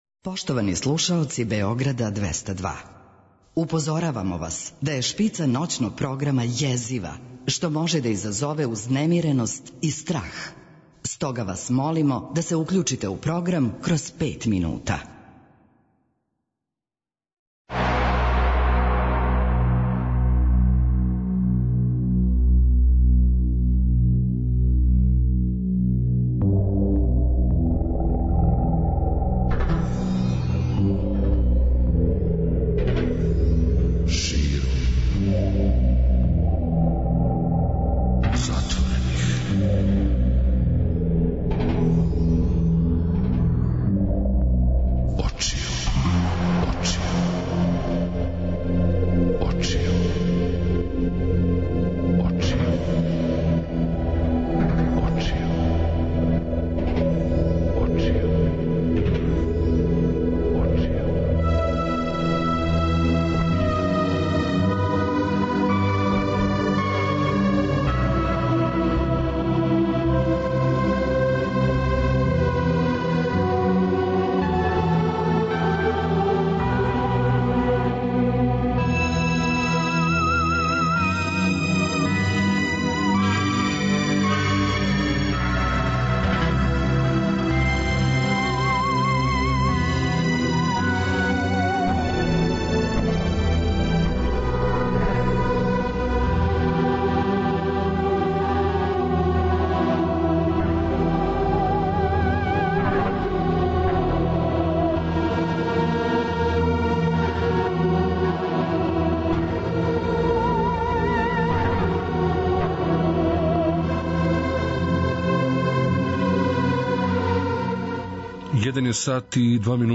преузми : 42.49 MB Широм затворених очију Autor: Београд 202 Ноћни програм Београда 202 [ детаљније ] Све епизоде серијала Београд 202 Летње кулирање Хит недеље Брза трака Домаћице и комшинице Топ листа 202